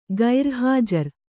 ♪ gair hājar